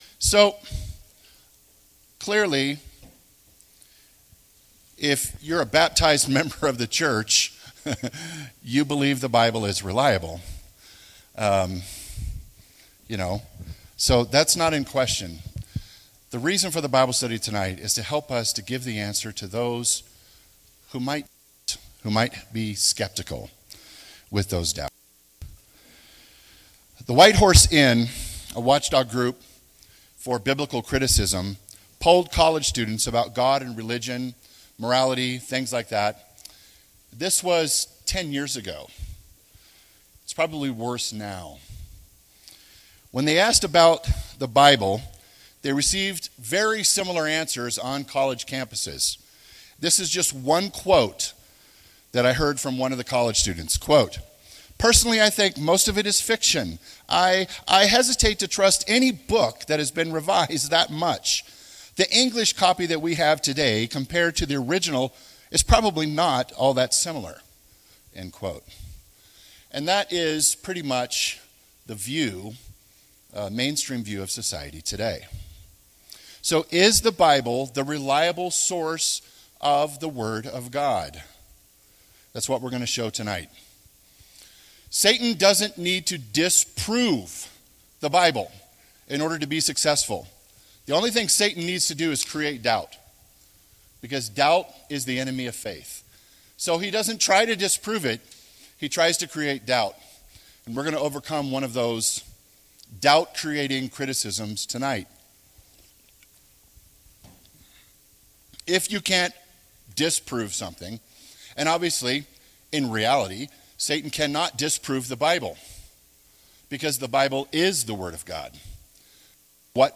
This sermon was given at the Estes Park, Colorado 2023 Feast site.